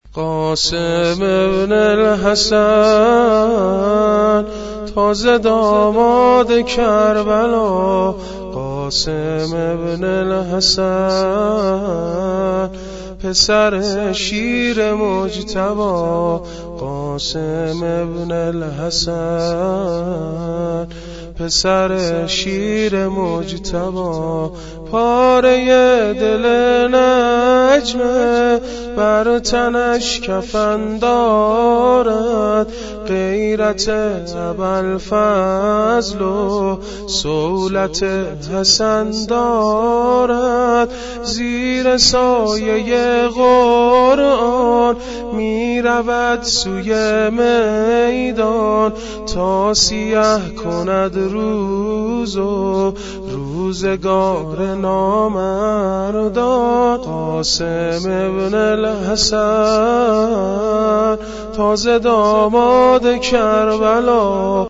قاسم بن الحسن - - -- - -زمينه شهادت حضرت قاسم (ع)- - -